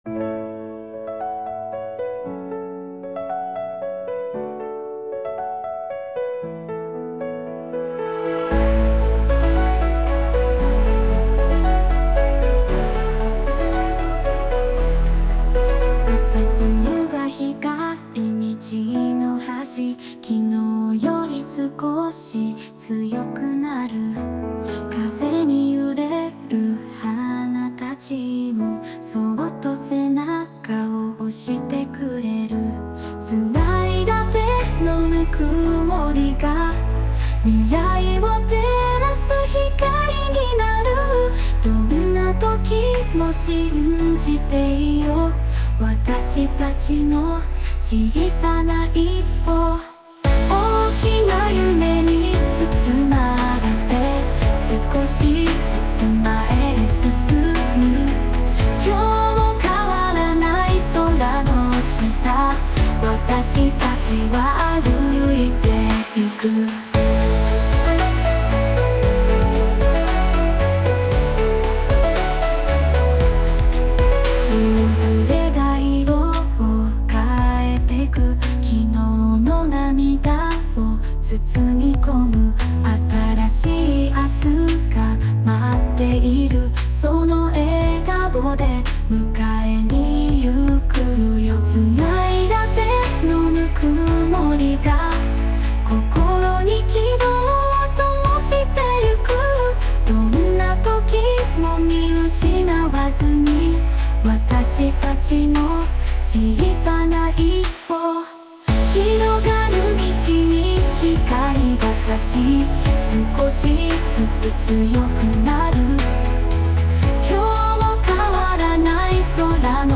邦楽女性ボーカル著作権フリーBGM ボーカル
著作権フリーオリジナルBGMです。
女性ボーカル（邦楽・日本語）曲です。
イメージ的には、某テレビ局の朝のドラマの主題歌をイメージしてます(笑)